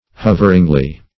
hoveringly - definition of hoveringly - synonyms, pronunciation, spelling from Free Dictionary Search Result for " hoveringly" : The Collaborative International Dictionary of English v.0.48: Hoveringly \Hov"er*ing*ly\, adv.